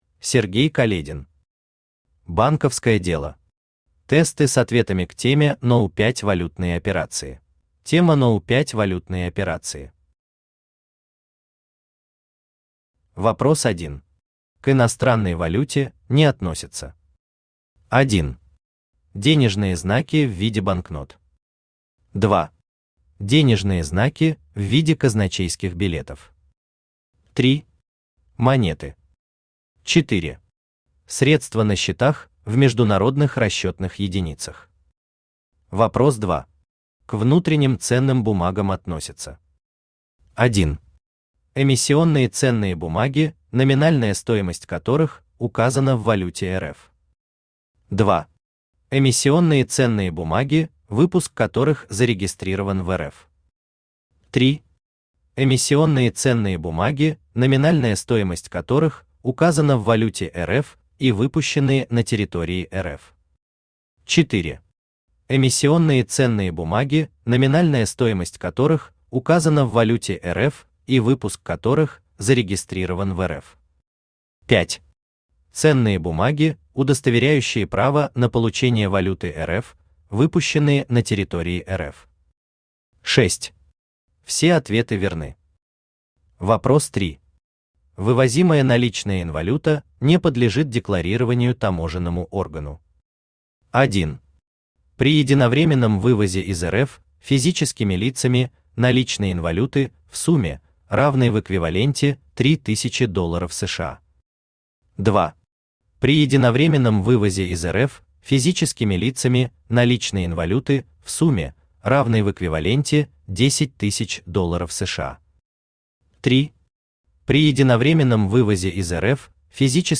Аудиокнига Банковское дело. Тесты с ответами к теме №5 «Валютные операции» | Библиотека аудиокниг
Тесты с ответами к теме №5 «Валютные операции» Автор Сергей Каледин Читает аудиокнигу Авточтец ЛитРес.